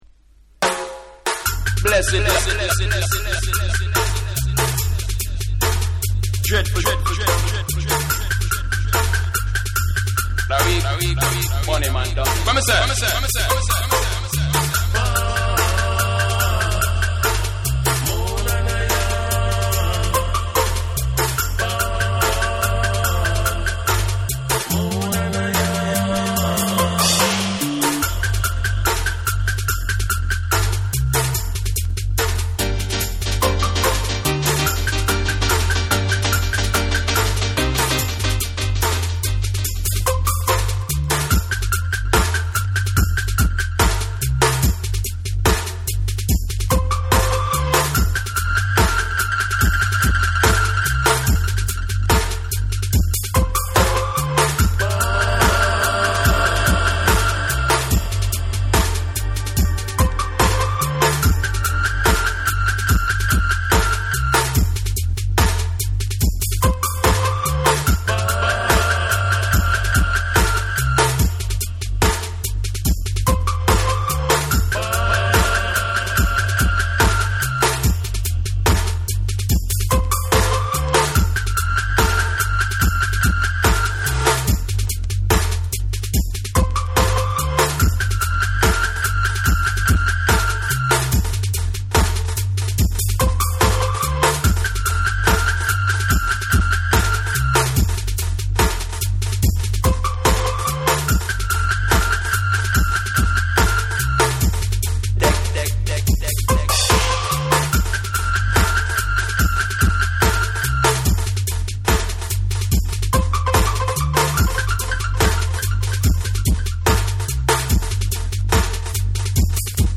REGGAE & DUB / ROOTS & CULTURE